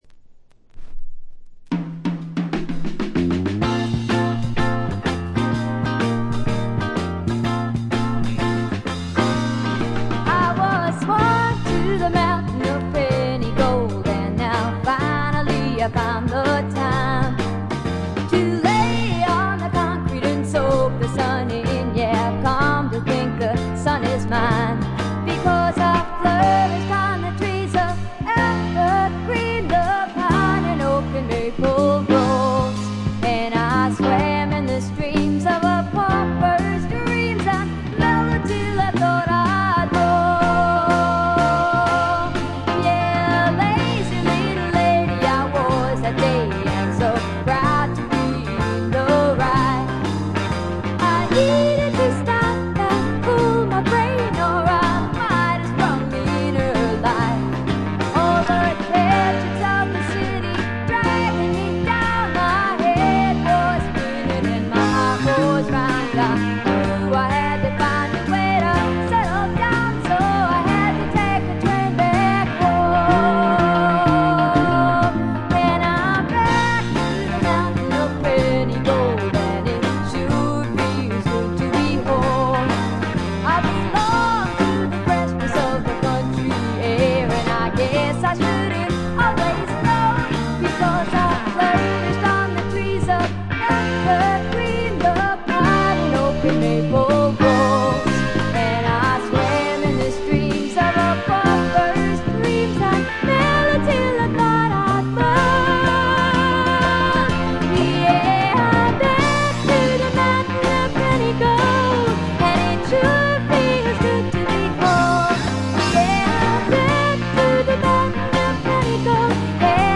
これ以外はところどころでチリプチ。
試聴曲は現品からの取り込み音源です。